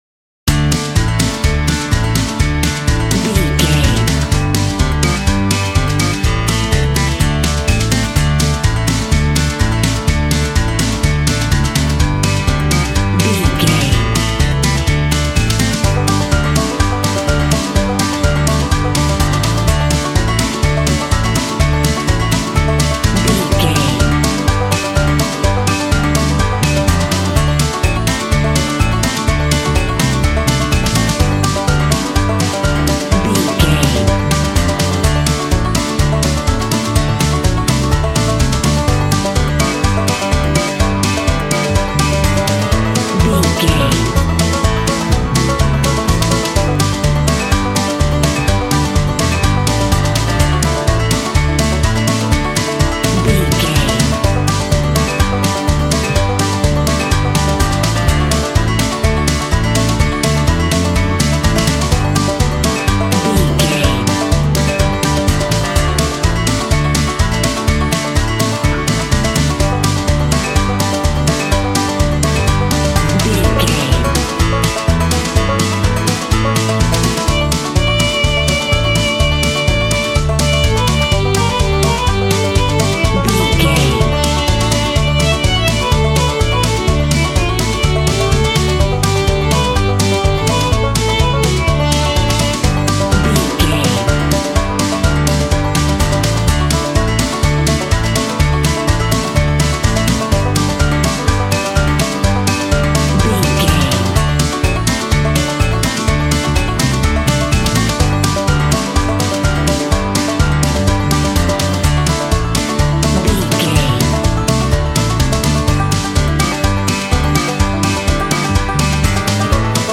Upbeat and uptempo fast paced country music.
Ionian/Major
D
bouncy
double bass
drums
acoustic guitar